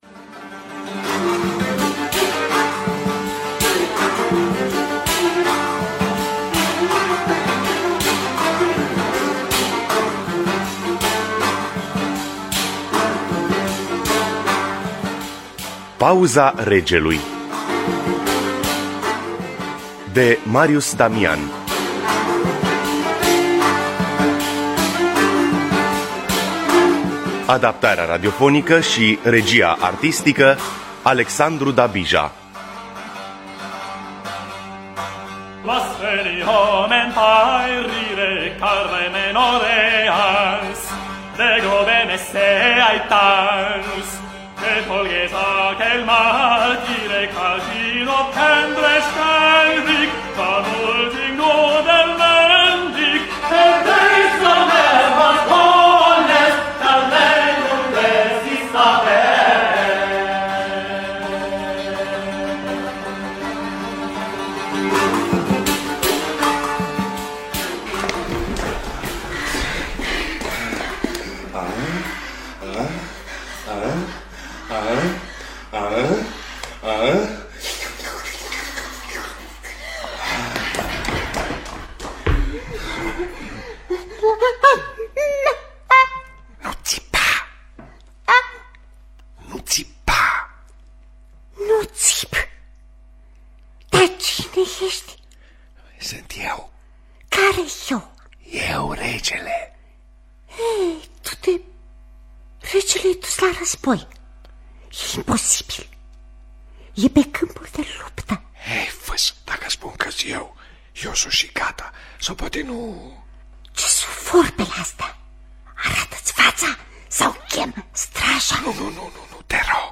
Teatru Radiofonic Online
În distribuţie: Marcel Iureş.